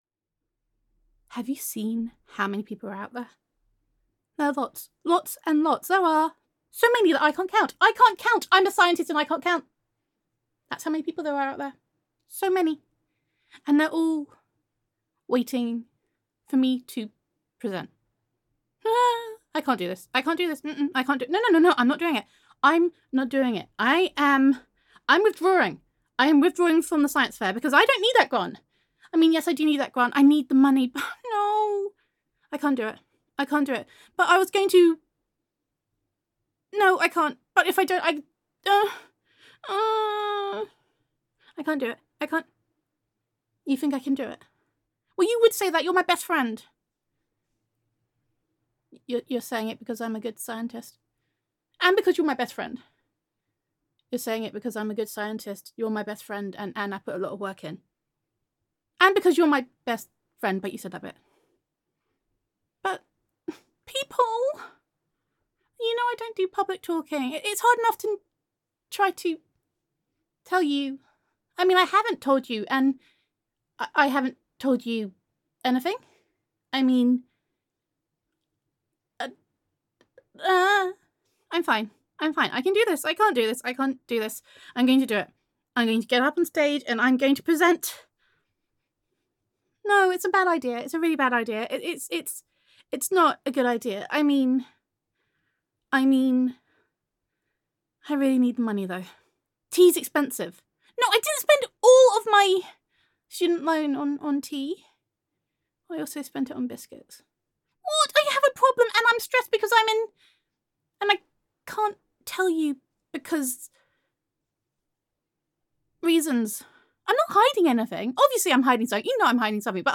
[F4A] The Science Fair
[Best Friend Roleplay]